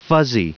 Prononciation du mot fuzzy en anglais (fichier audio)
Prononciation du mot : fuzzy